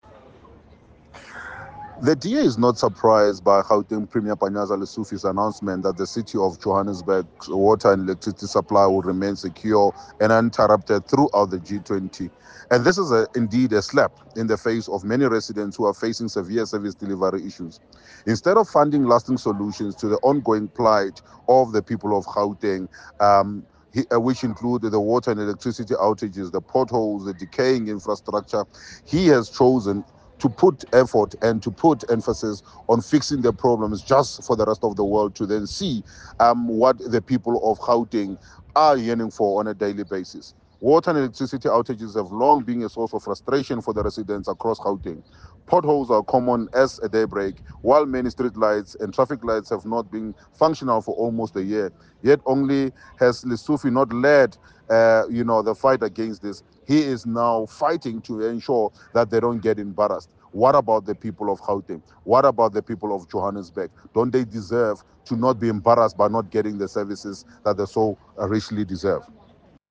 soundbite by Solly Msimanga MPL.